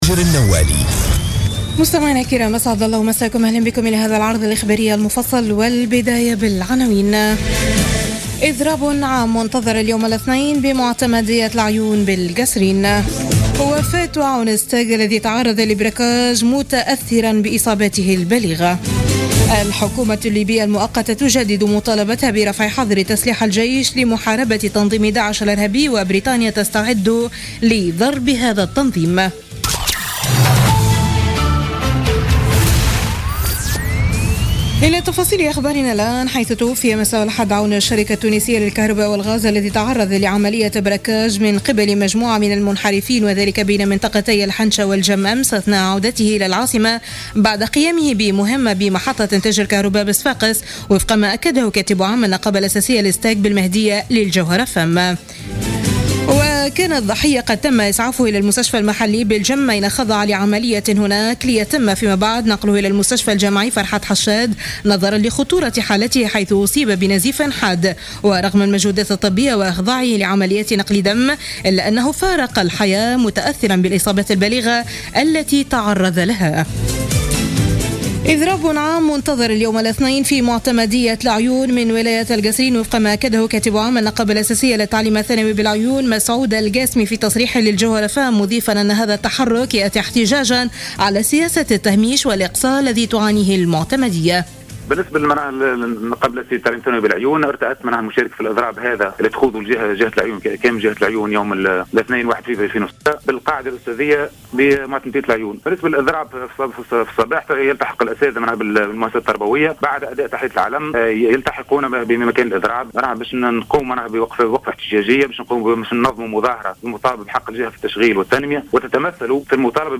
نشرة أخبار منتصف الليل ليوم الإثنين 1 فيفري 2016